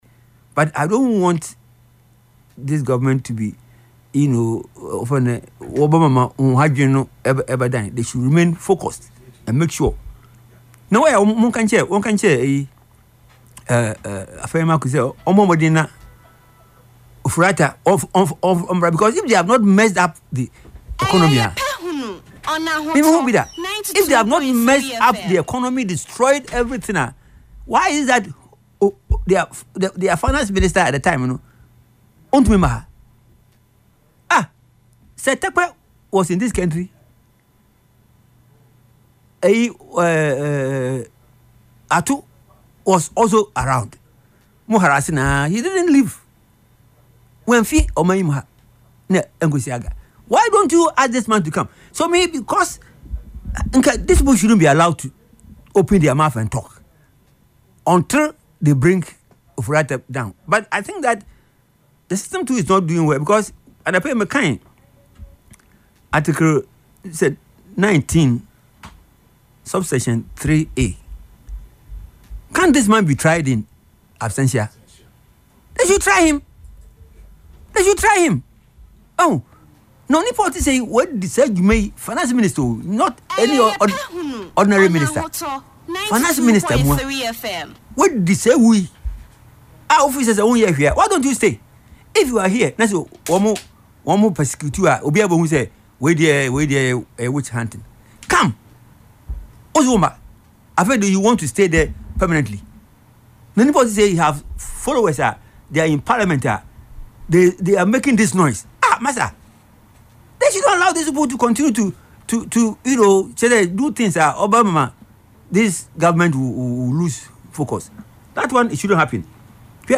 Speaking on Ahotor FM’s Yepe Ahunu programme on Saturday, March 21